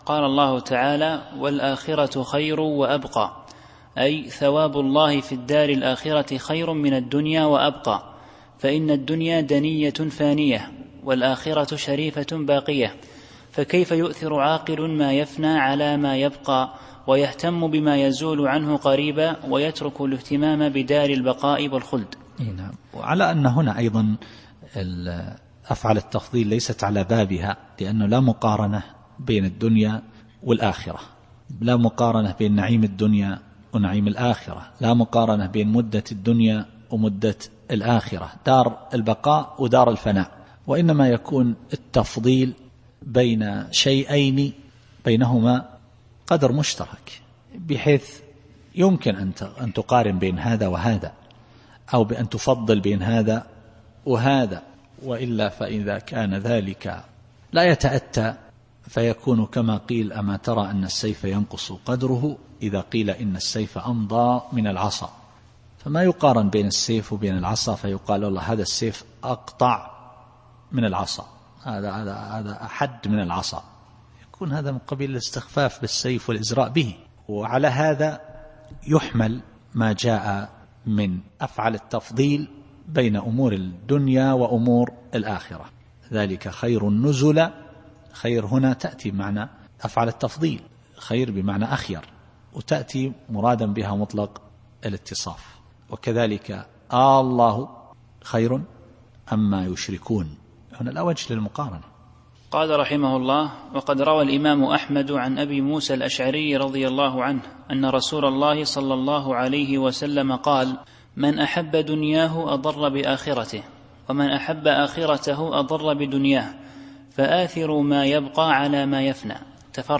التفسير الصوتي [الأعلى / 17]